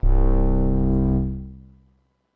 描述：通过Behringer调音台采样到MPC 1000。它听起来一点也不像风的部分，因此被称为Broken Wind。
Tag: 模拟 多样品 合成器 虚拟模拟